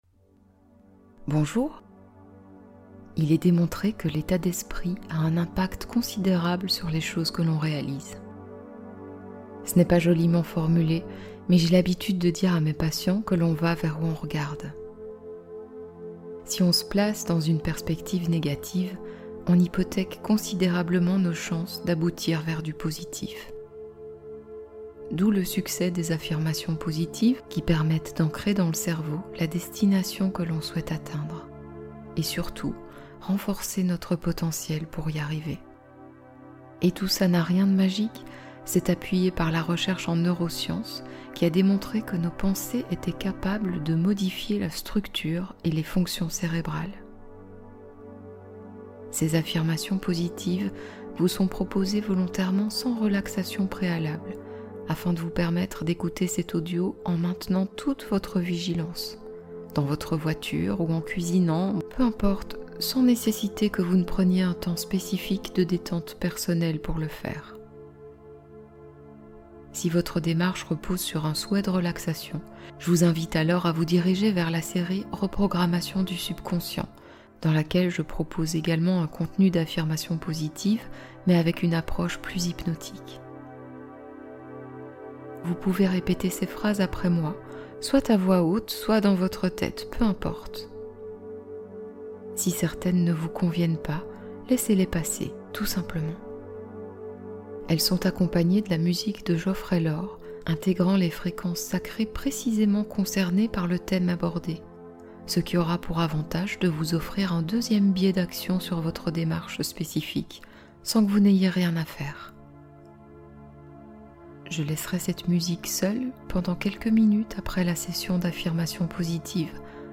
Calmez stress, angoisse et anxiété instantanément | Affirmations positives + Fréquences sacrées